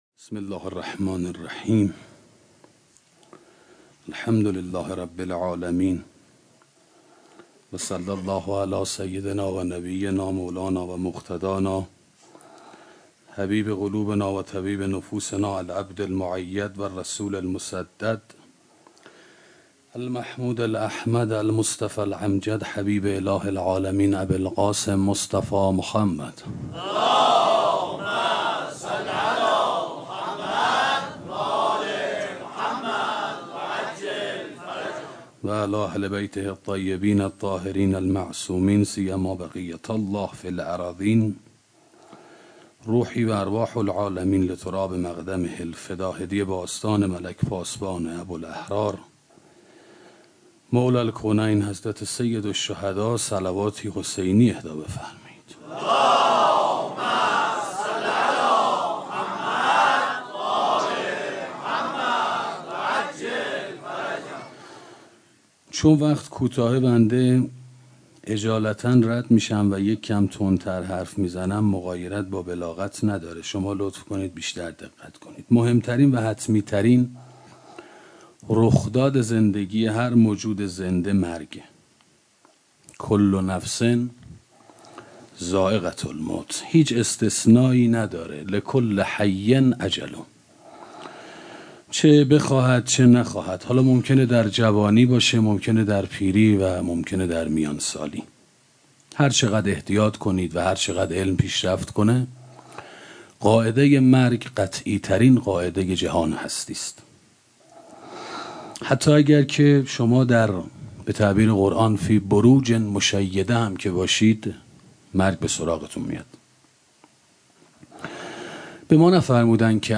سخنرانی اندیشه و انگیزه 1 - موسسه مودت